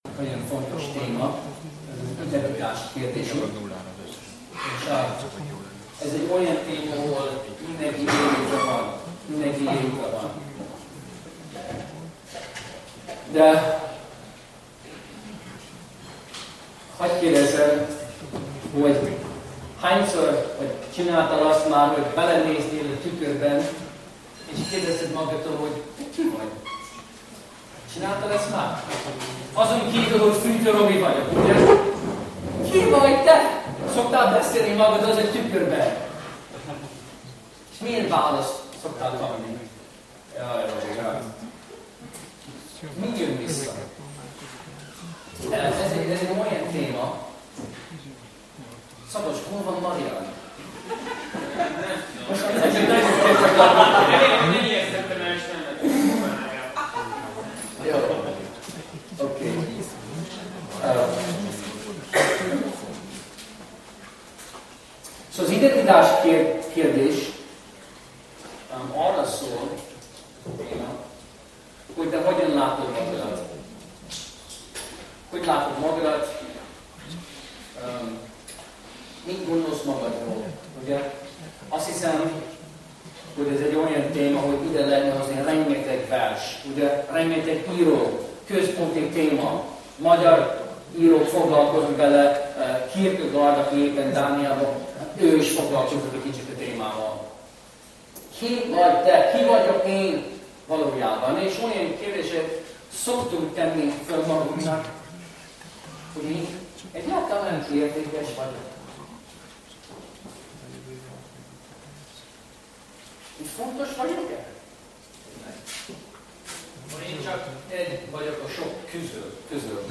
Ezekre a húsba vágó kérdésekre kereshetjük a választ az előadásban.